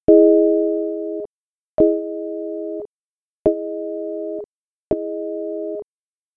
DR (Decay) and SL (Sustain)
EXAMPLE: DR slider being raised from minimum (longest decay time) to maximum, with SL set to minimum: